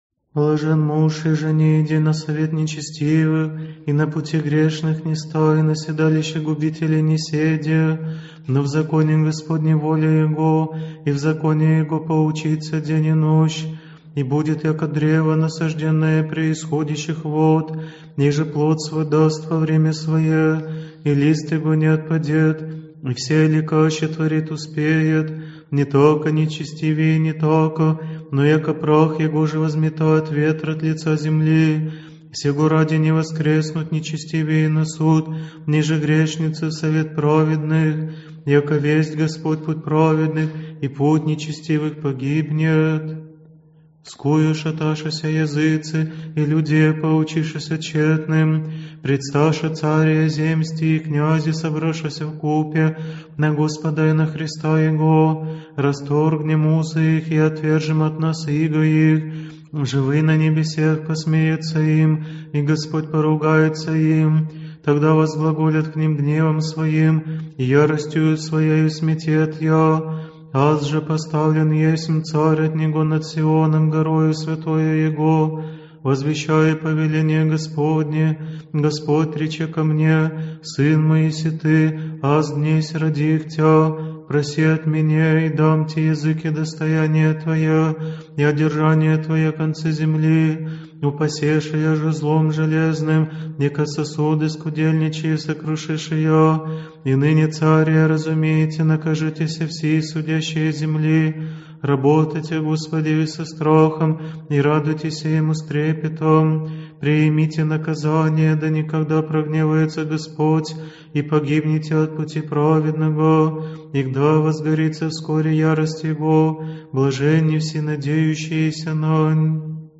Аудиокнига Псалтирь | Библиотека аудиокниг